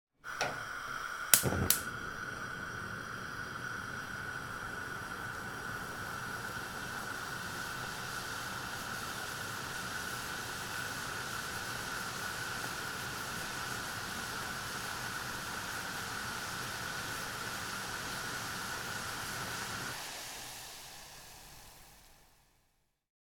Звук зажженной газовой плиты для кипячения воды